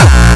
VEC3 Bassdrums Dirty 16.wav